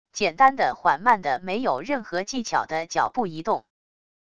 简单的缓慢的没有任何技巧的脚步移动wav音频